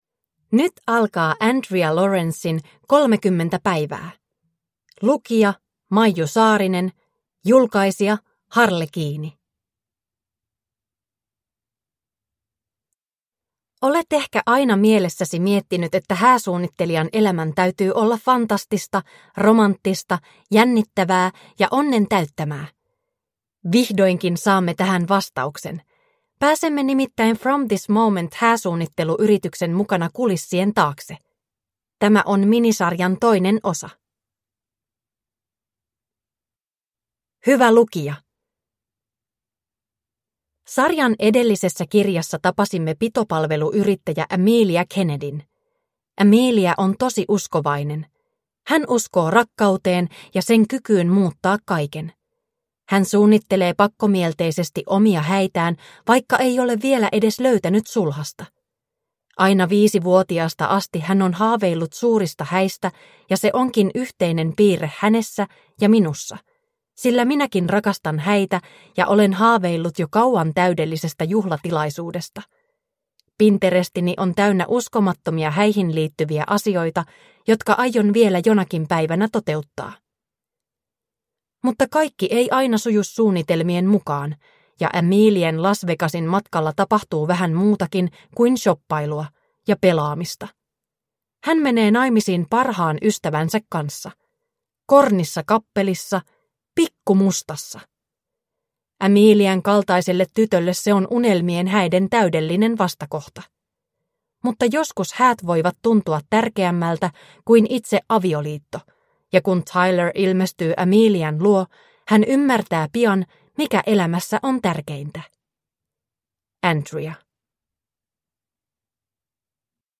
Kolmekymmentä päivää (ljudbok) av Andrea Laurence